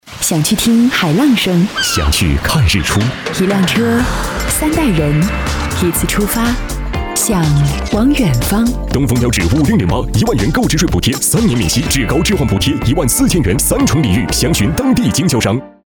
A男139号
【广告】男女对播东风标致